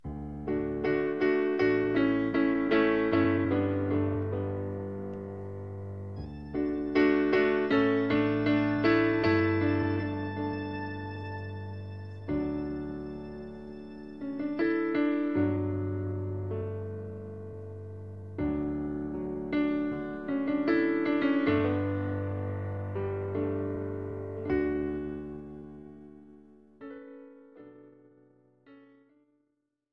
This is an instrumental backing track cover.
• Key – D
• With Backing Vocals
• No Fade